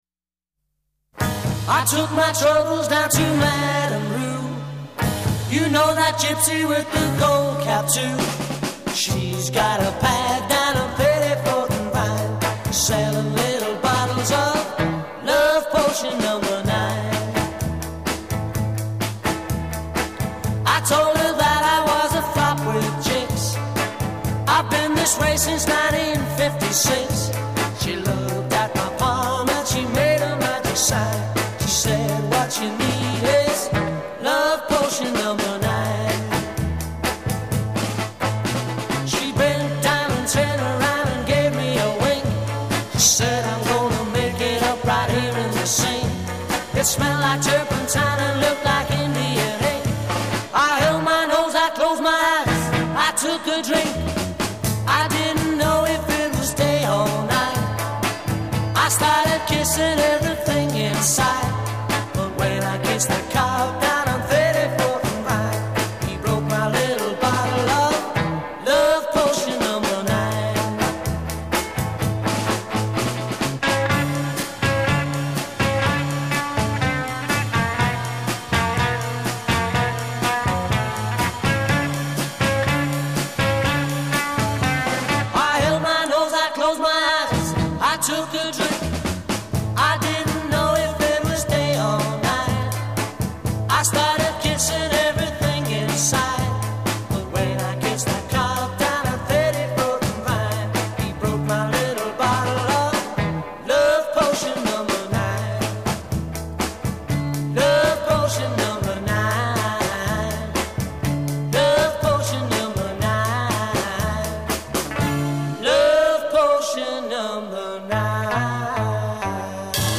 vocals & guitar
vocals and bass guitar
drums and vocals
A verse 0:00 8+2 double tracked vocal; hook at end of line a
B chorus : 6+2 guitar solo; final cadence is sung c'